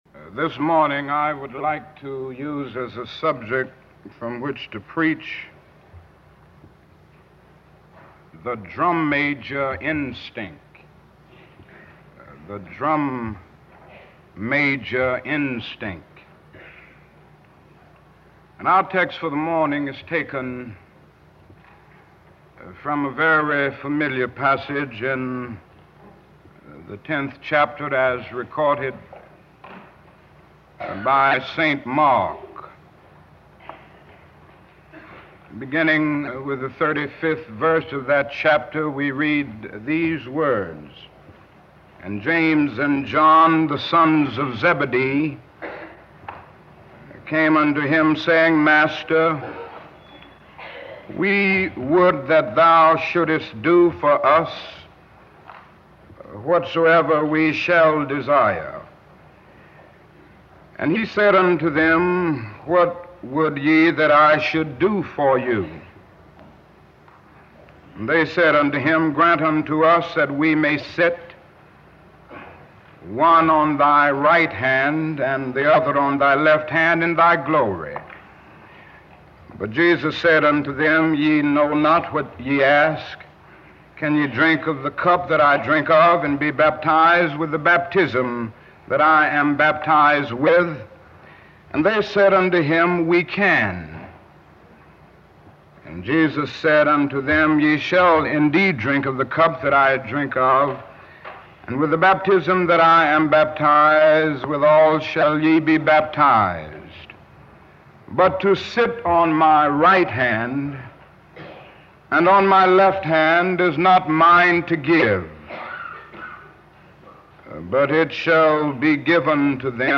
On this birthday of Martin Luther King Jr., celebrated during times of racist violence and white supremacist insurrection unleashed in the name of “American greatness”, we give full attention to one of MLK’s most powerfully transformative sermons: The Drum Major Instinct, delivered at Ebenezer Baptist Church on February 4, 1968.